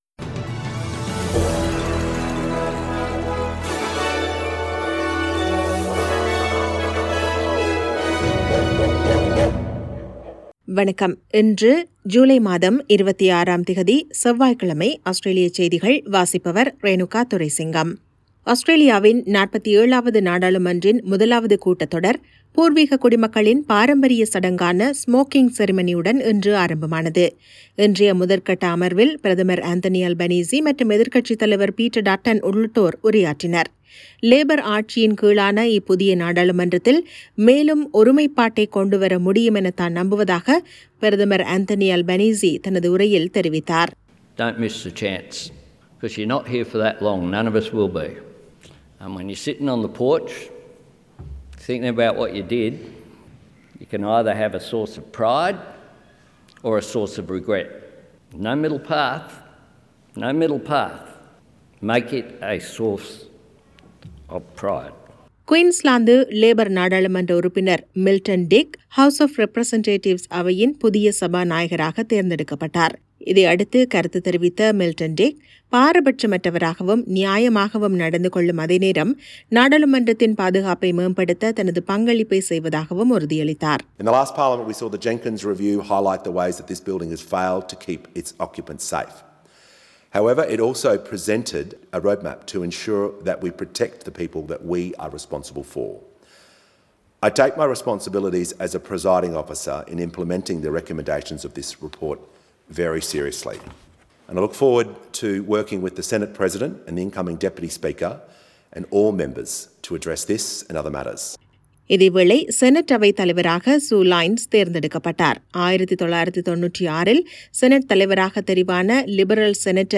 Australian news bulletin for Tuesday 26 July 2022.